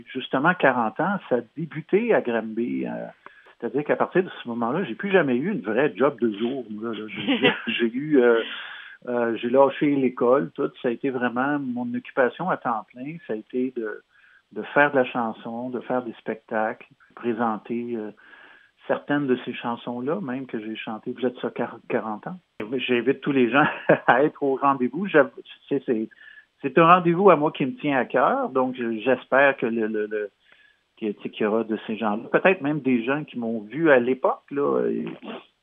Luc De Larochellière en entrevue avec M105 : « Granby, c’est là que tout a commencé pour moi » - M105 - FM 104,9 - Station de radio de Granby